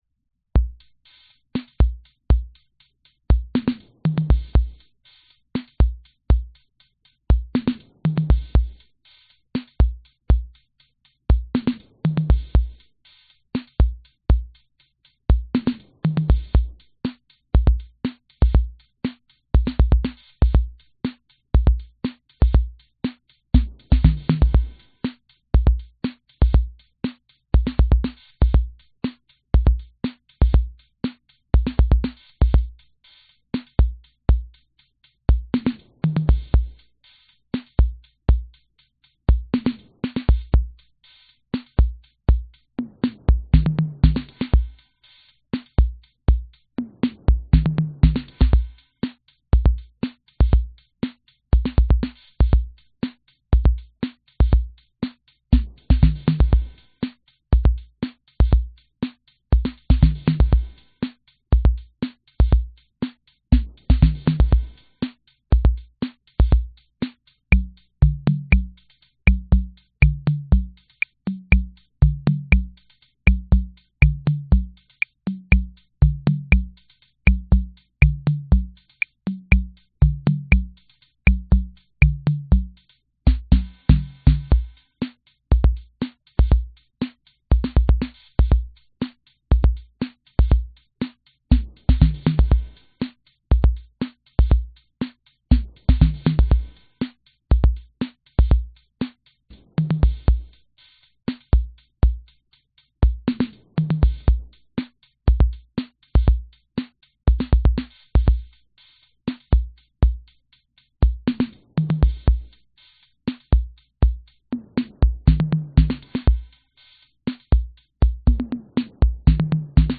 Korg Rhythm 55B复古模拟
描述：KR55B是在1980年生产的。这个野兽是模拟的，所以我尽力将电位器设置为120。
Tag: 模拟 电子 复古